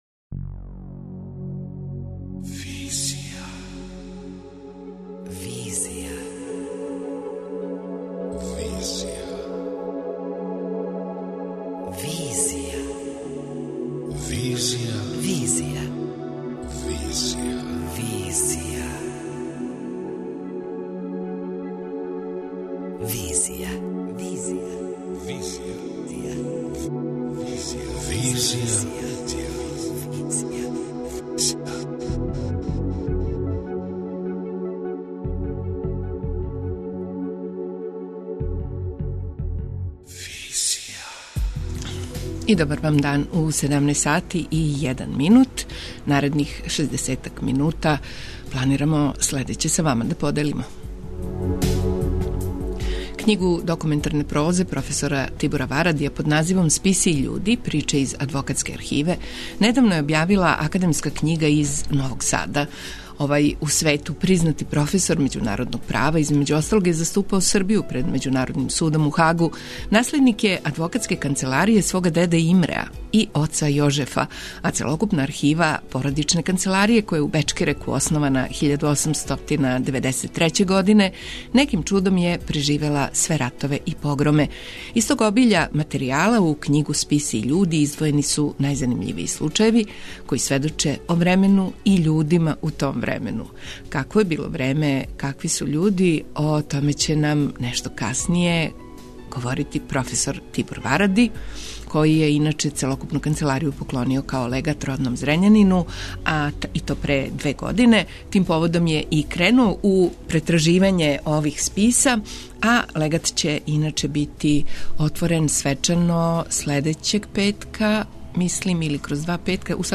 Какво је било време, и какви људи, о томе у данашњој емисији говори професор Тибор Варади , који је целокупну канцеларију поклонио као легат родном Зрењанину.
преузми : 27.62 MB Визија Autor: Београд 202 Социо-културолошки магазин, који прати савремене друштвене феномене.